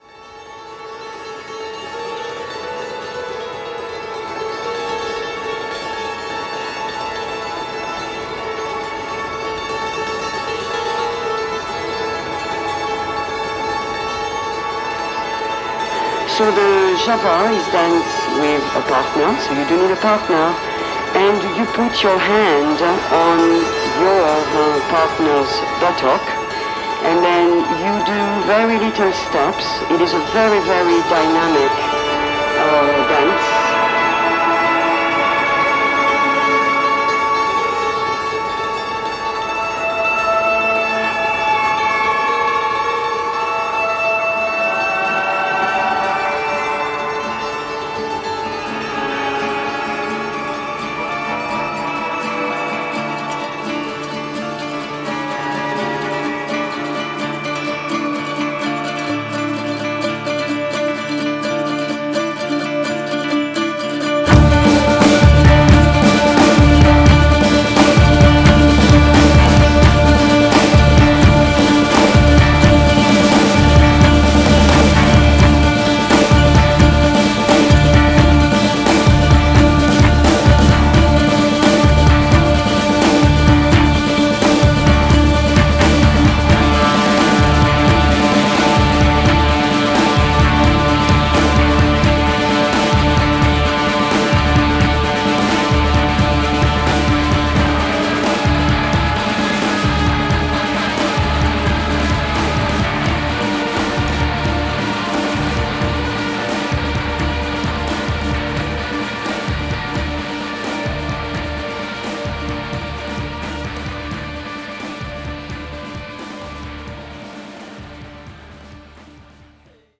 The mystical allure and strange interludes